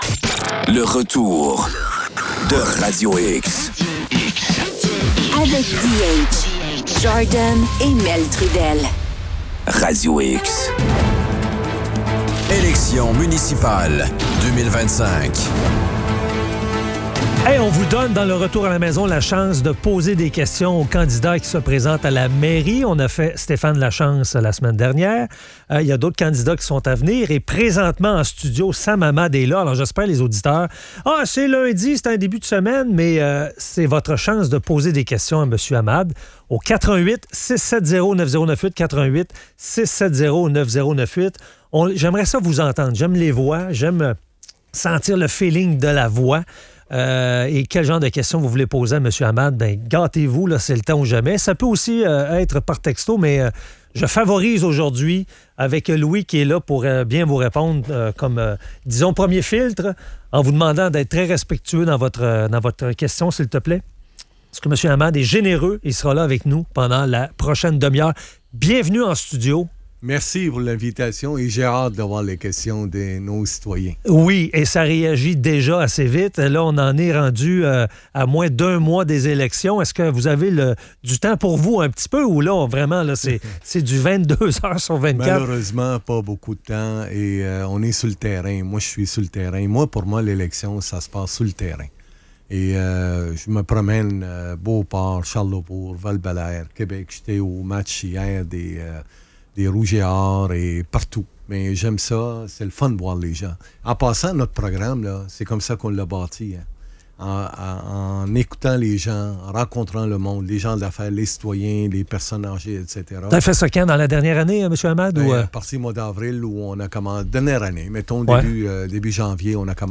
Sam Hamad répond aux questions des auditeurs!